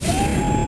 Descarga de Sonidos mp3 Gratis: robot 3.
servomotor3.mp3